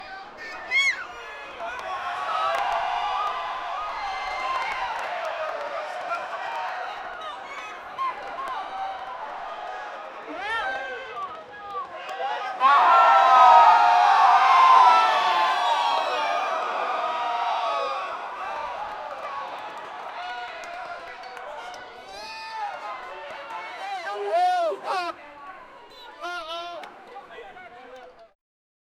High School crowd booing with babies high pitch saying bitch in distants 0:29 Created Oct 27, 2024 12:57 AM Funny bedsheet ghost woman saying “Oooooooooooooooooooooo!”
high-school-crowd-booing--xi4usdee.wav